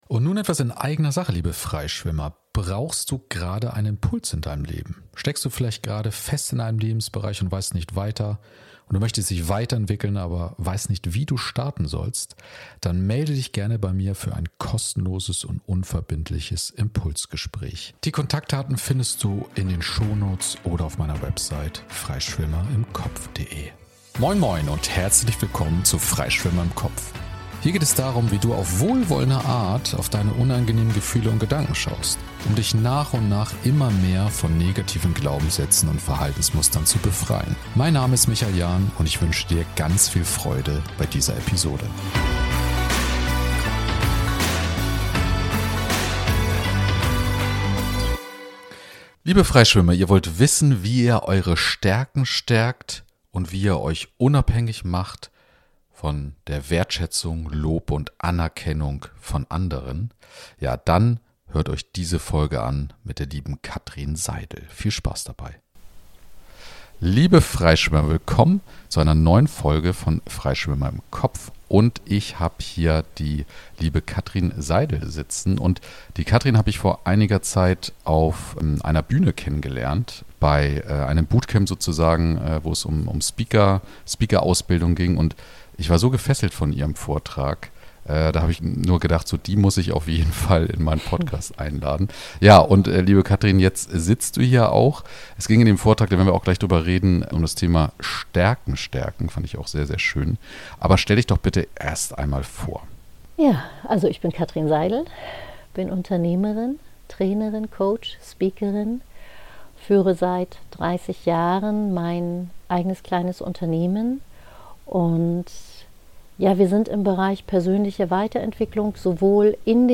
040: Erkenne Deine Stärken und mache dich unabhängig von der Anerkennung im Außen! Im Gespräch